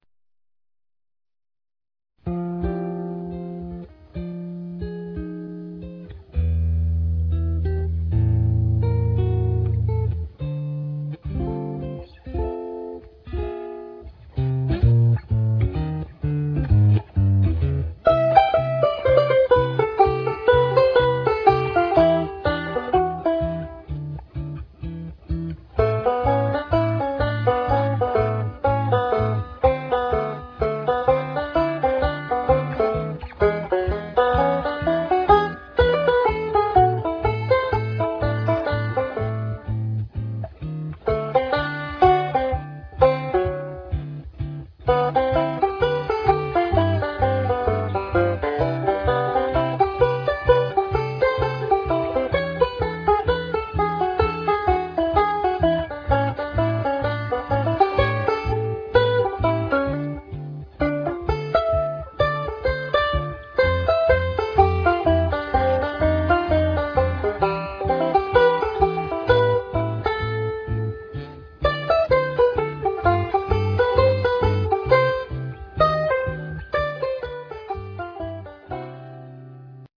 solos you'll be learning.